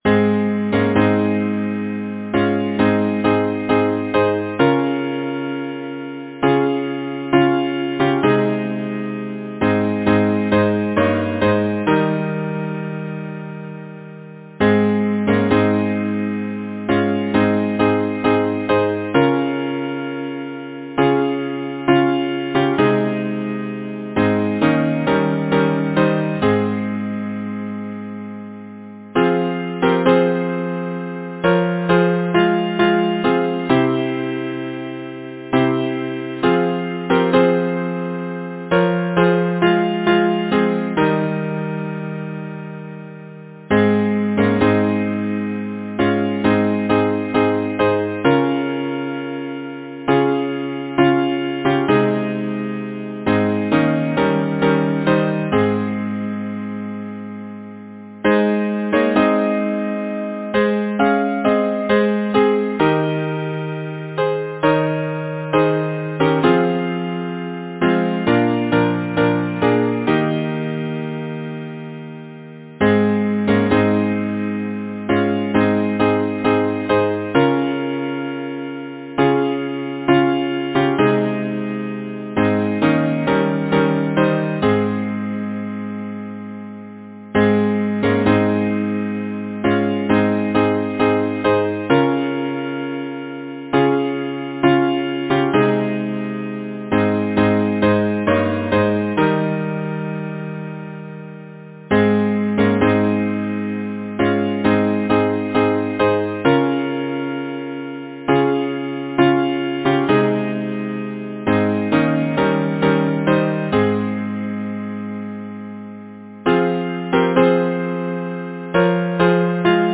Title: Lillie of the snow-storm Composer: Henry Clay Work Lyricist: Number of voices: 4vv Voicing: SATB Genre: Secular, Partsong
Language: English Instruments: A cappella